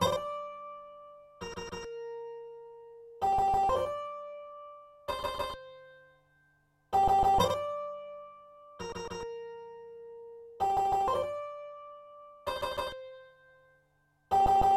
140个时髦的低音3
描述：今天，时髦的简单贝司
Tag: 140 bpm Electro Loops Bass Loops 590.80 KB wav Key : Unknown